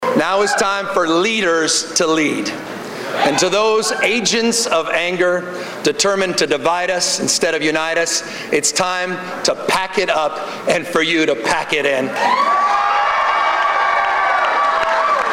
Democratic Lt. Governor Gavin Newsom is going to become the 40th Governor of California. He gave his victory speech after his opponent, Republican businessman John Cox, called him to concede from the race.